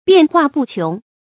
變化不窮 注音： ㄅㄧㄢˋ ㄏㄨㄚˋ ㄅㄨˋ ㄑㄩㄥˊ 讀音讀法： 意思解釋： 指變化多種多樣，沒有窮盡。